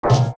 sound effect as the Megaton Hammer when he hits a wall in Majora's Mask.
OOT_MegatonHammer_Hit.wav